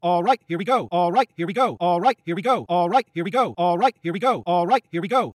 描述：一个奇怪的人声样本，我以60 bpm的速度录制，然后以115 bpm的速度掌握这个样本。
标签： 115 bpm Electronic Loops Vocal Loops 930.28 KB wav Key : Unknown
声道立体声